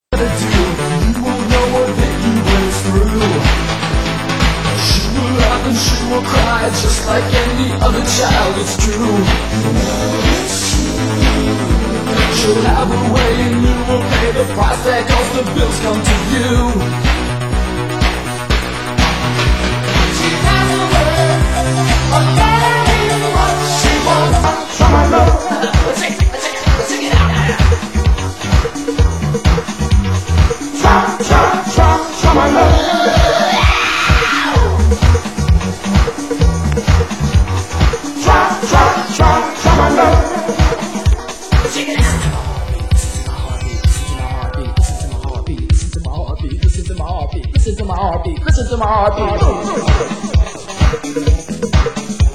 Genre: Disco